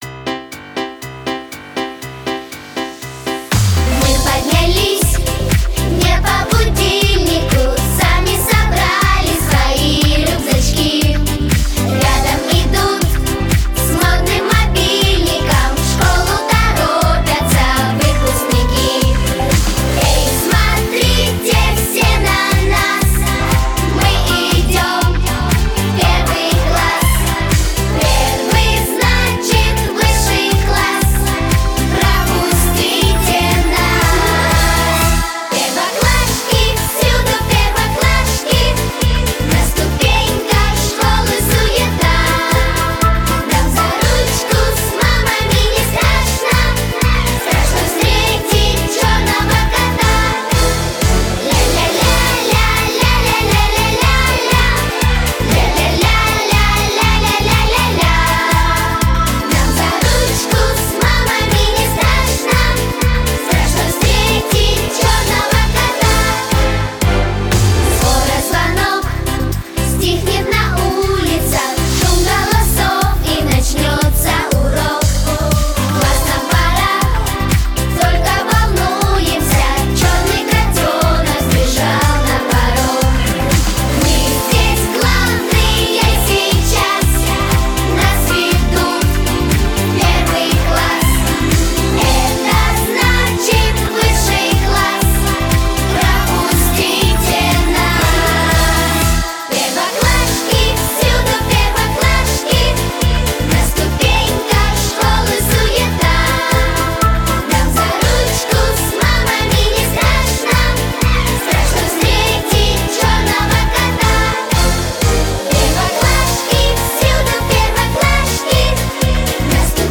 Песни про школу